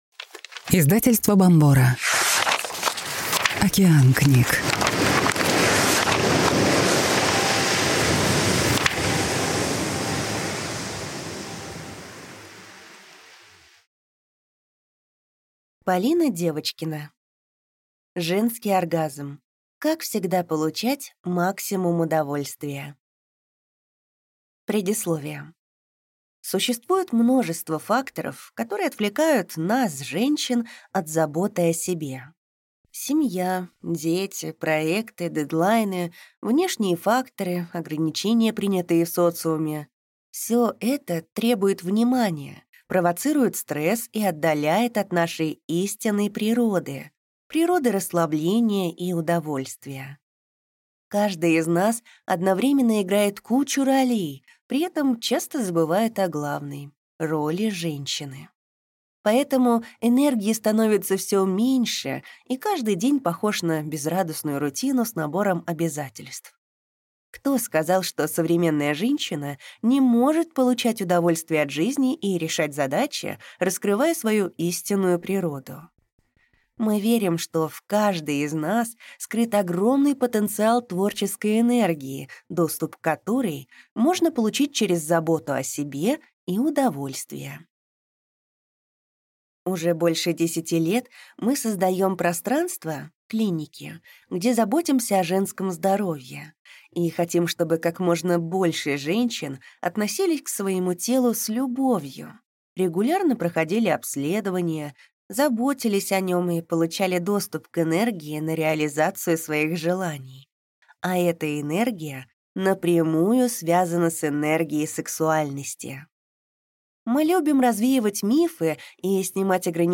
Аудиокнига Женский оргазм. Как всегда получать максимум удовольствия | Библиотека аудиокниг